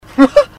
Laugh 1